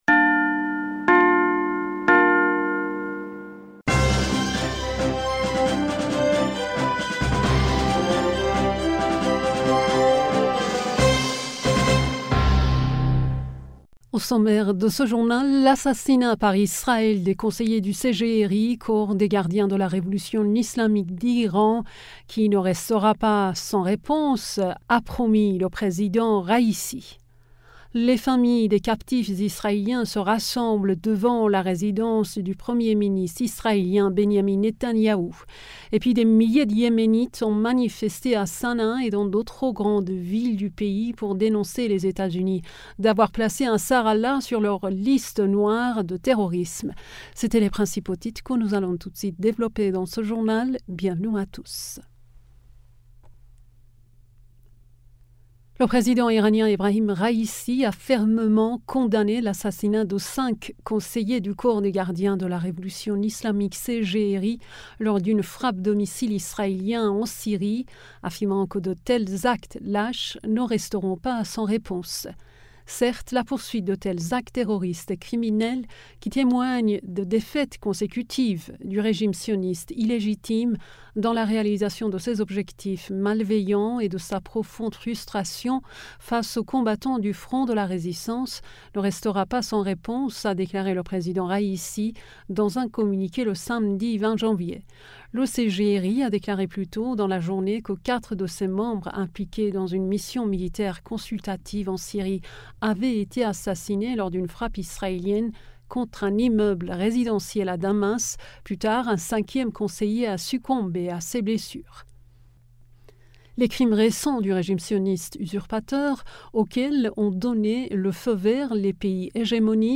Bulletin d'information du 21 Janvier 2024